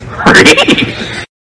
Hehehehehehe Sound Effect Download: Instant Soundboard Button